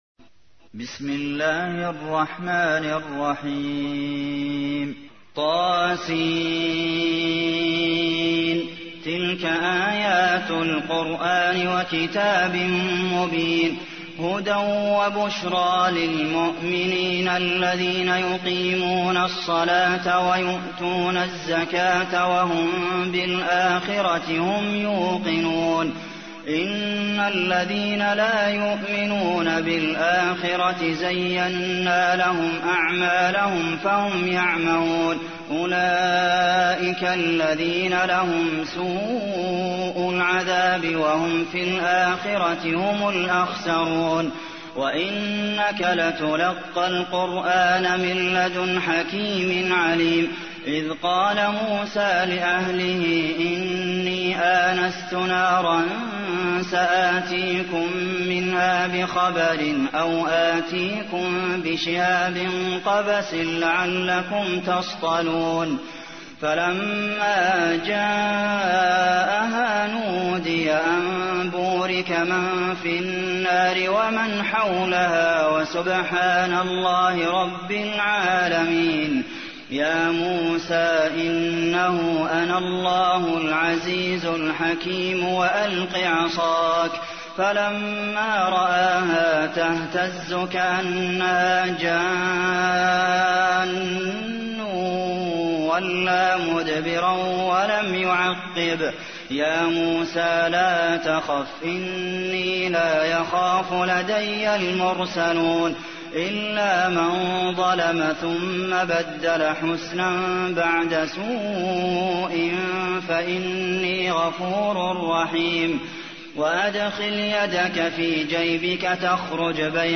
تحميل : 27. سورة النمل / القارئ عبد المحسن قاسم / القرآن الكريم / موقع يا حسين